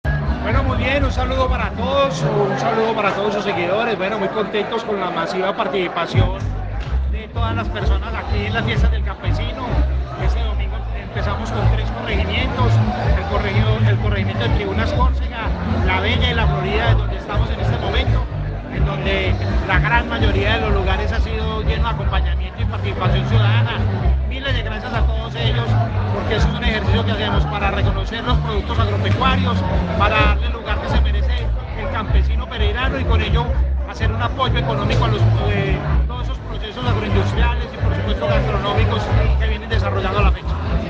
Audio_Alcalde.mp3